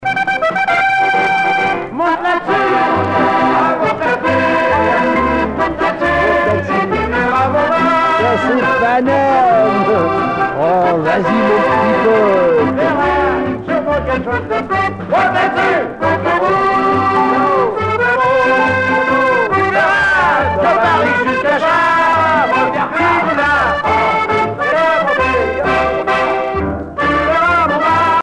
danse : one-step
Pièce musicale éditée